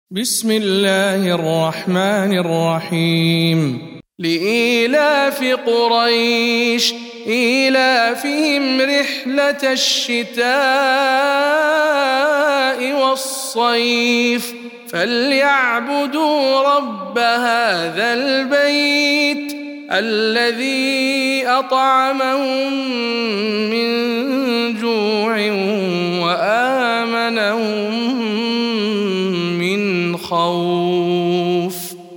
سورة قريش - رواية الدوري عن الكسائي